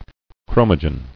[chro·mo·gen]